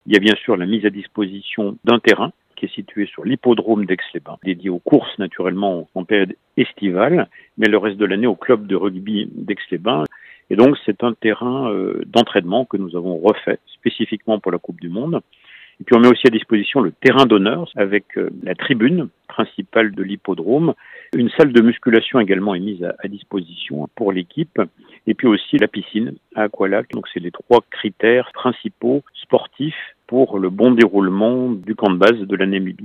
Le maire d’Aix-les-Bains, Renaud Beretti, revient sur les installations mises en place: